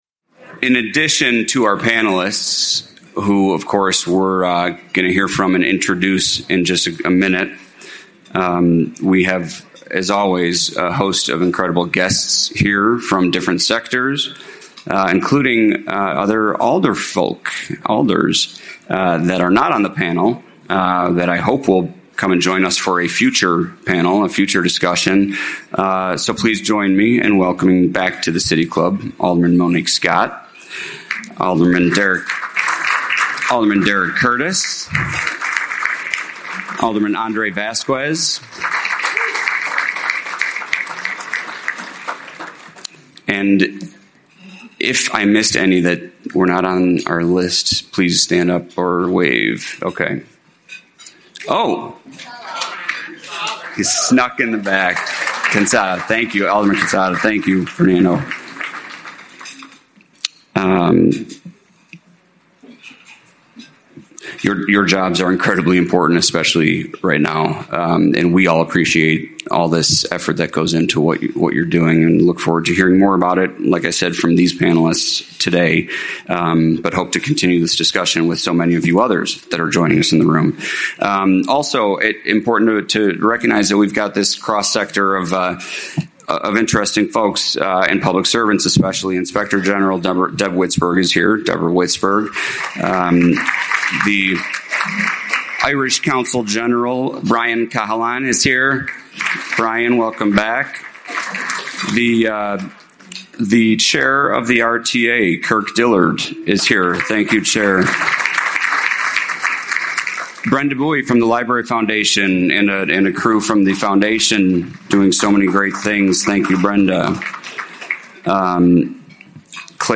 Inside the 2026 City of Chicago budget: four aldermen, real tradeoffs, and fresh updates. With a welcome from Alderman Brian Hopkins, this program will include the latest in an ever-changing budget discussion, from closing gaps and funding public safety to pensions, transit, and equitable development across all 77 communities